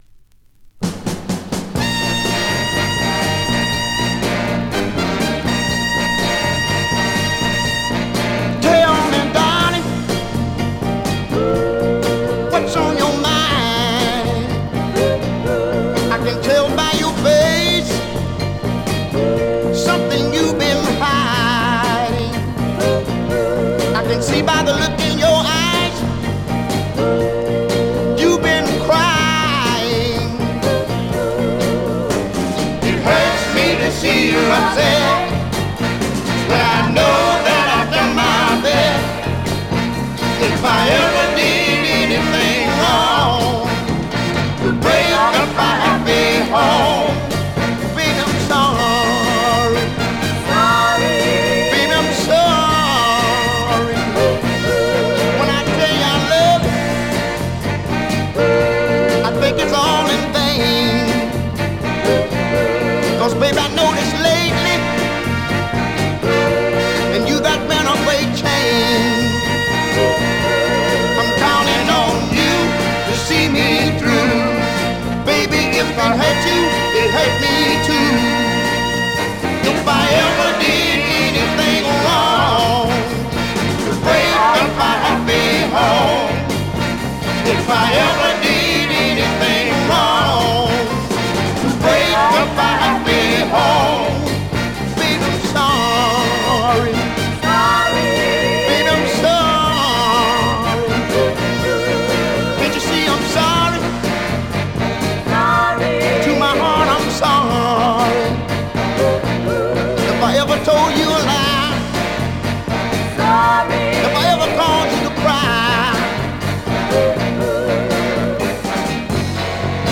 Great dancer from this popular artist